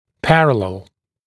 [‘pærəlel][‘пэрэлэл]параллельный